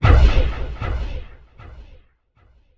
MAGIC_SPELL_Dark_Pulse_Echo_stereo.wav